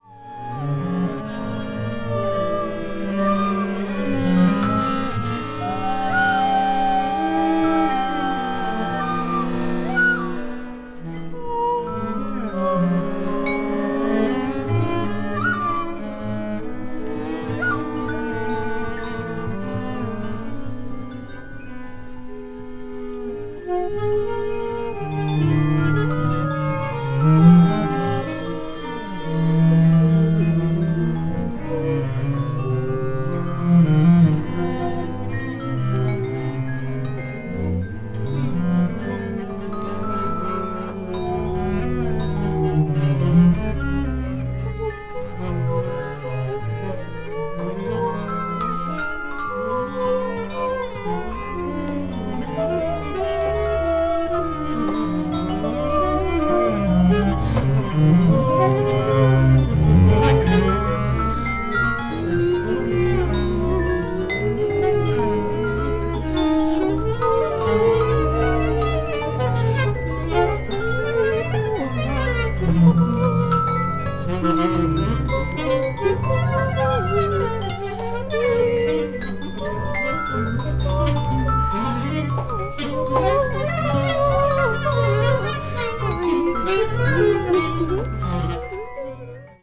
media : EX/EX(some slightly noise.)
Recorded in New York
a nine-member ensemble
vocals
clarinet
alto saxophone
avant-jazz   free improvisaton   free jazz   spiritual jazz